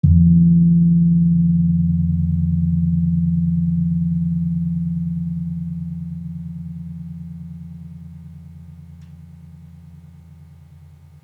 Gamelan Sound Bank
Gong-F2-f.wav